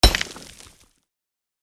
Mining_1.mp3